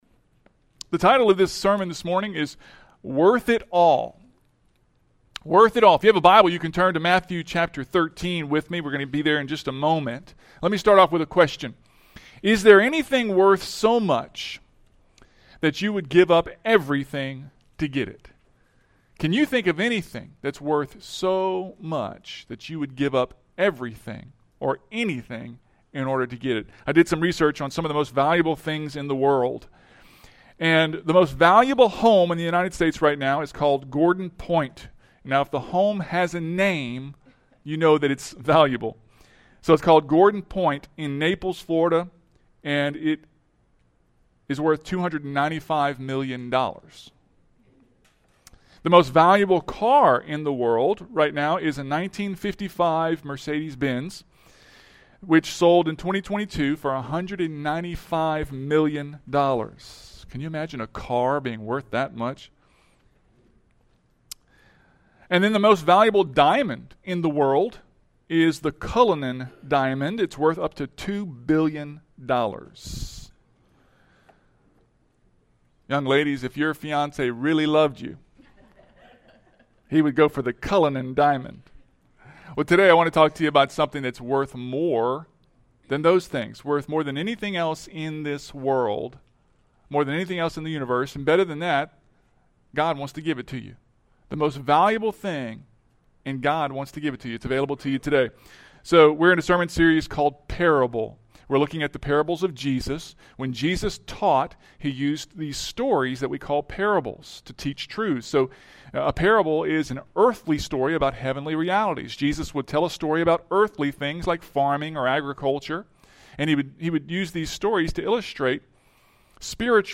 Matthew 13:44-46 Parable – Week 2 Sermon